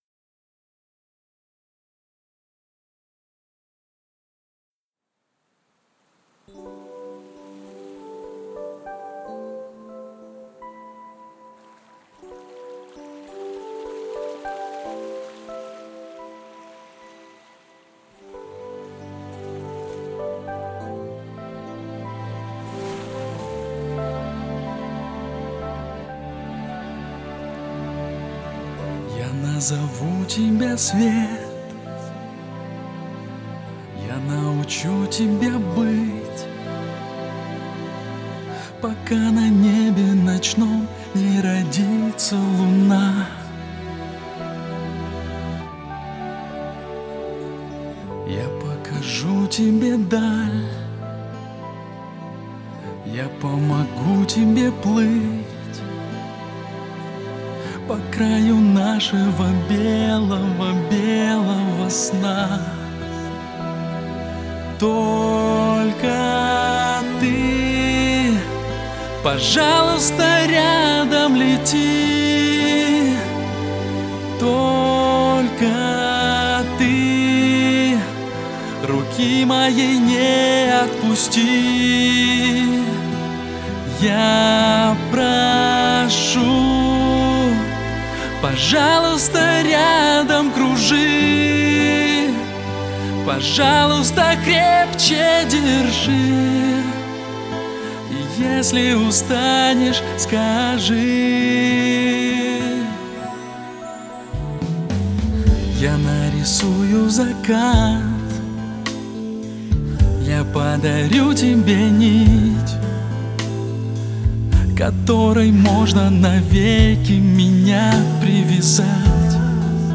там звук плохой - вам точно нужно ?